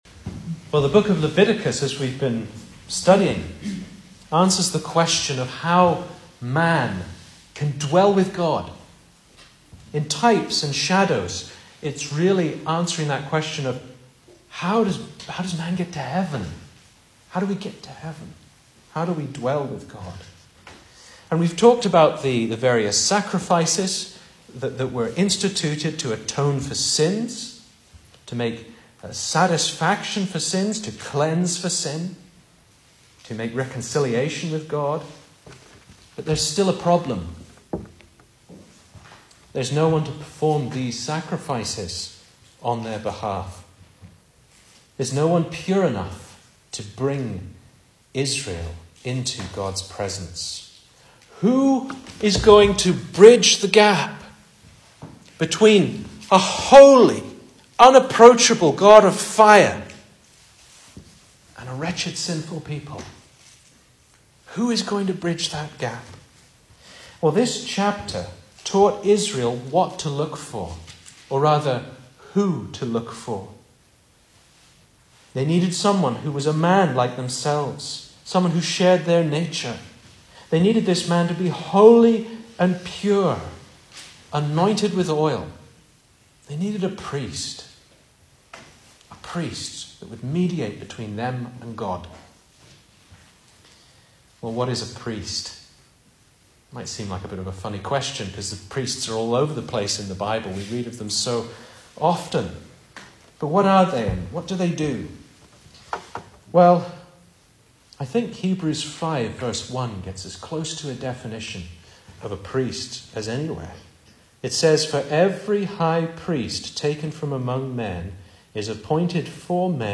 2024 Service Type: Sunday Evening Speaker